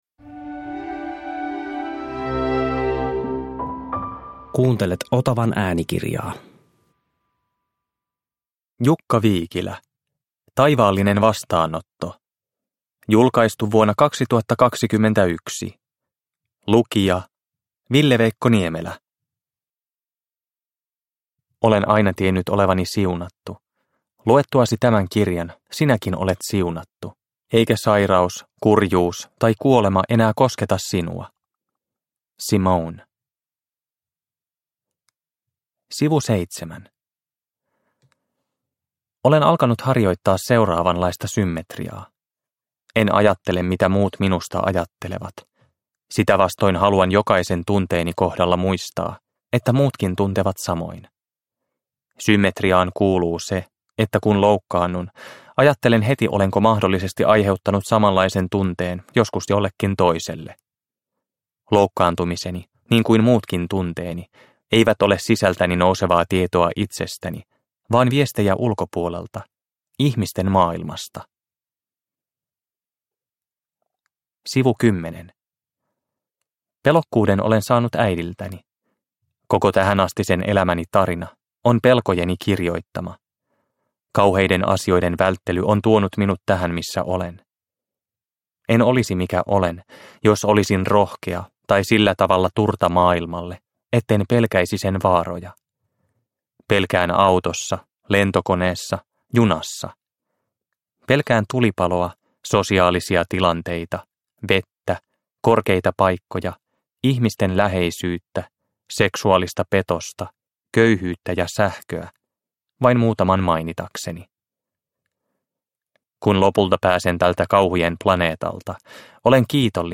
Taivaallinen vastaanotto – Ljudbok – Laddas ner